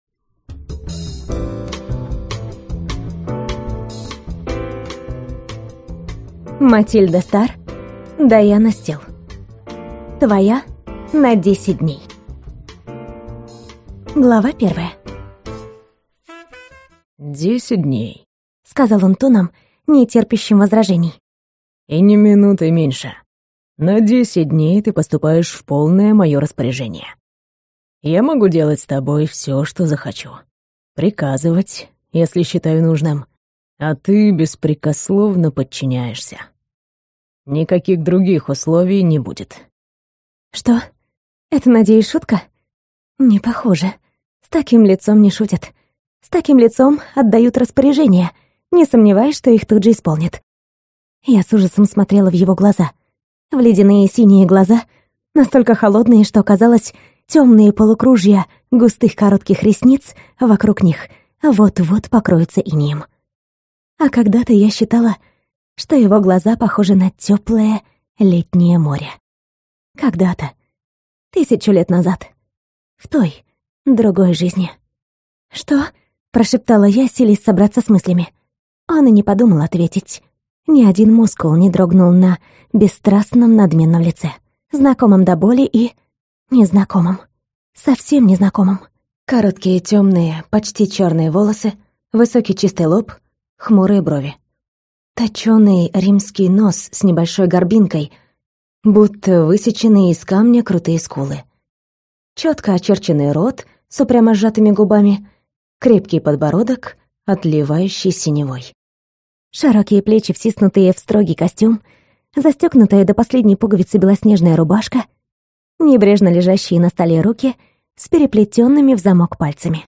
Аудиокнига Твоя на 10 дней | Библиотека аудиокниг